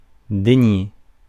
Ääntäminen
IPA : /ˌkɒndɪˈsɛnd/
IPA : /ˌkɑːndɪˈsɛnd/